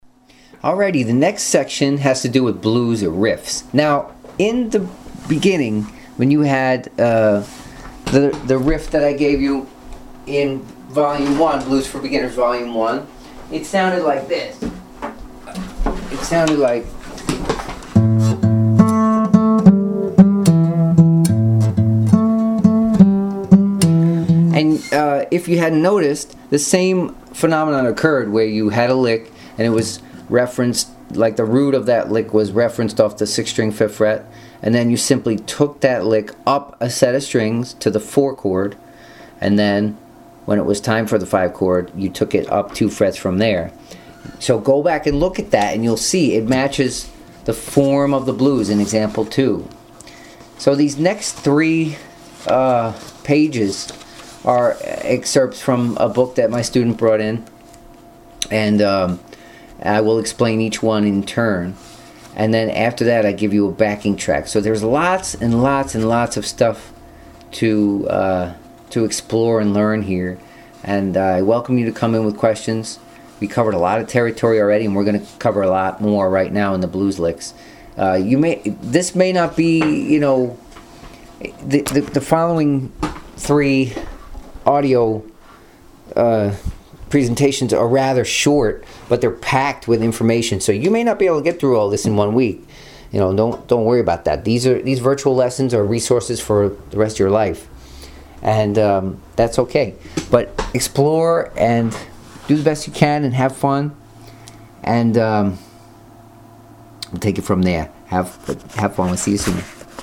Blues, part 3 - Guitar Lessons in Myrtle Beach, SC
blues-for-beginners-part-3-13.mp3